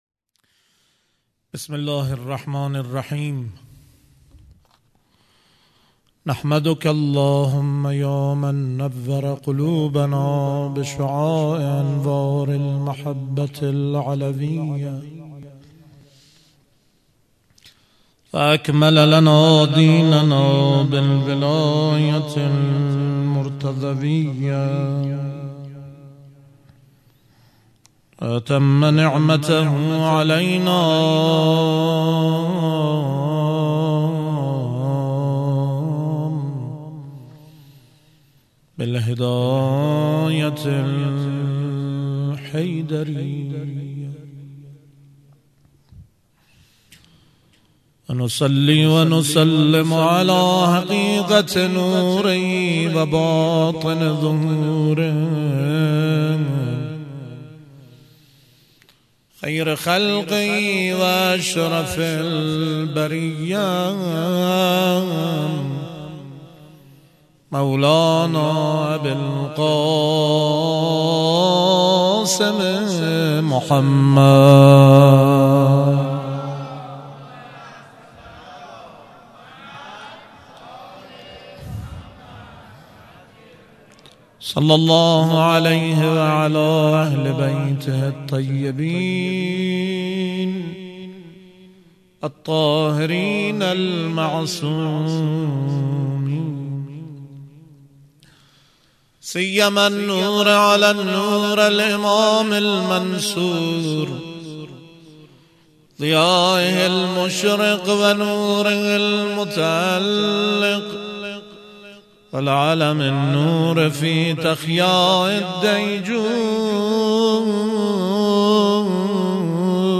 خیمه گاه - هیئت مکتب الزهرا(س)دارالعباده یزد - سخنرانی